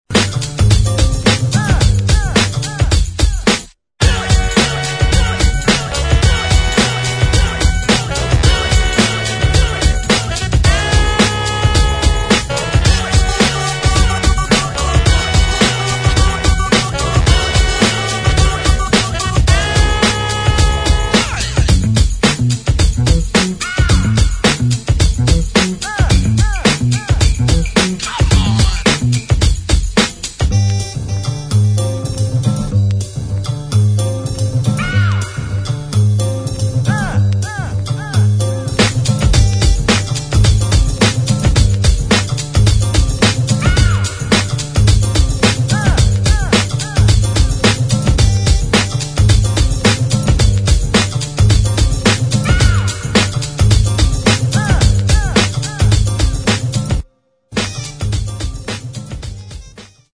[ HIP HOP ]
Instrumental